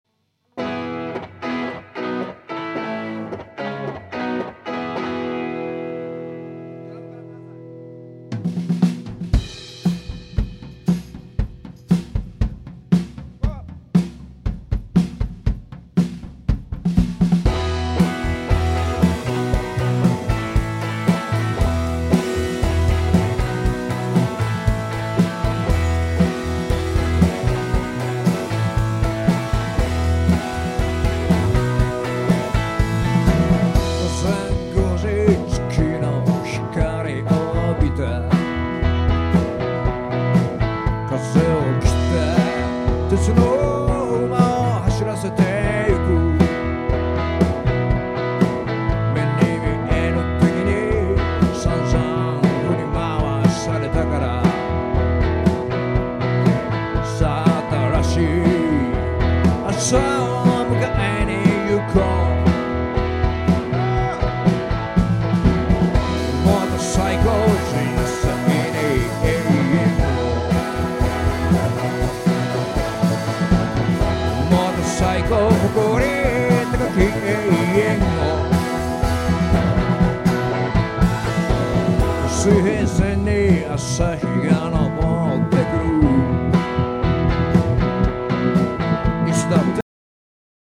「Motorcycle」を初めてバンドで演奏した日のリハーサルの音源があります。30分くらい演奏して、形ができてきた頃かな。
曲をドラムから始めようとする明確な意思とか、ぜんぜん歌えてない初々しさとか。
Motorcycle_band.mp3